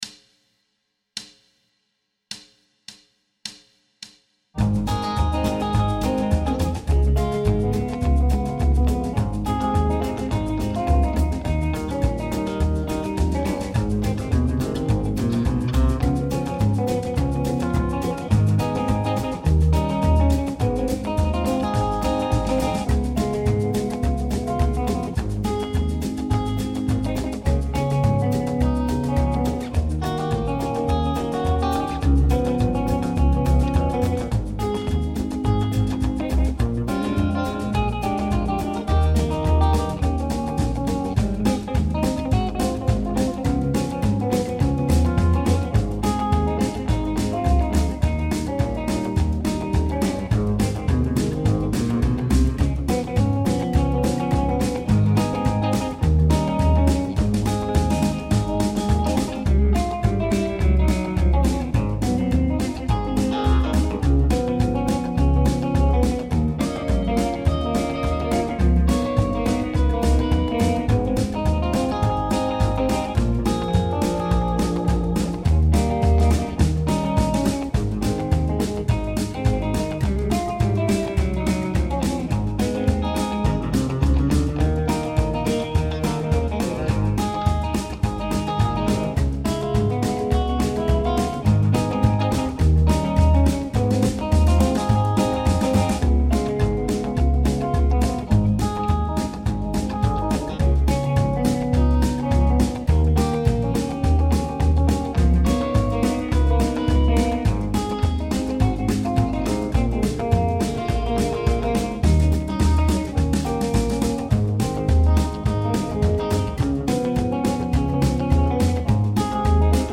Rockabilly 8 Bar Blues
Rockabilly jam tracks. 8 bar blues, 50’s / 60’s style.
Tempo: 105BPM
Chord Progression: I-IV7-V7
Key of Ab – with guitar
rockabilly-8-bar-105-ab.mp3